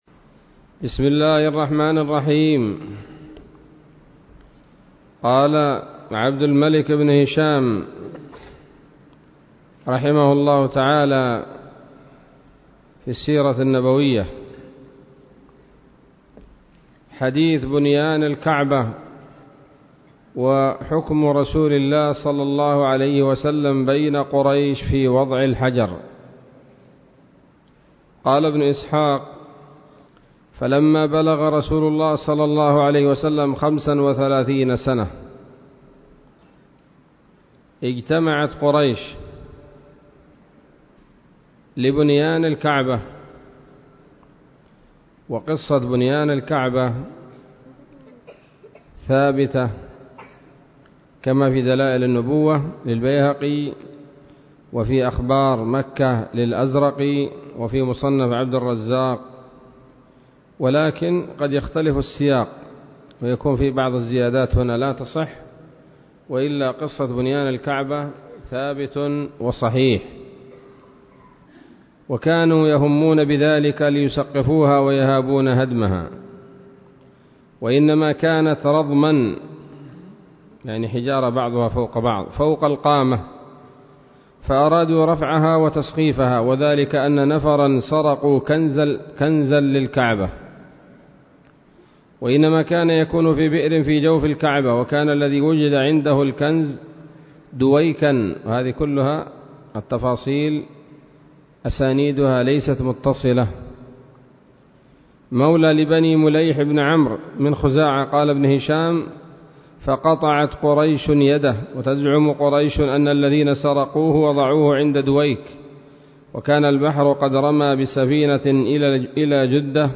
الدرس السادس عشر من التعليق على كتاب السيرة النبوية لابن هشام